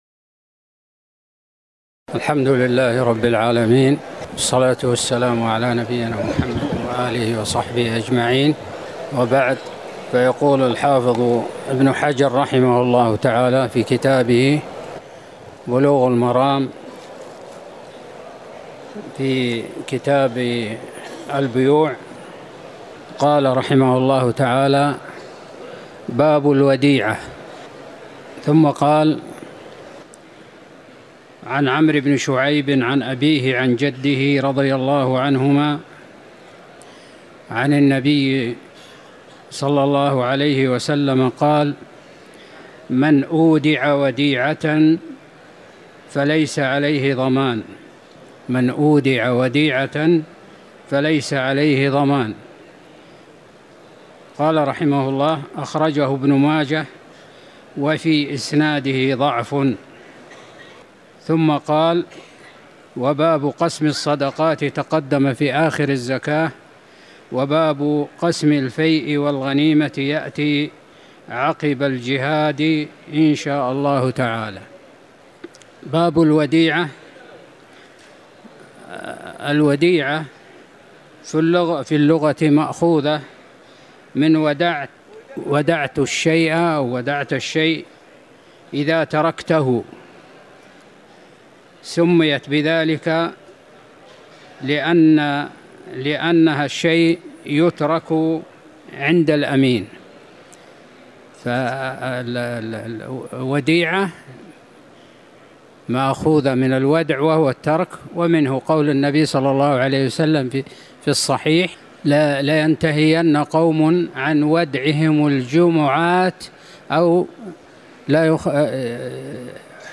تاريخ النشر ٤ محرم ١٤٤١ هـ المكان: المسجد النبوي الشيخ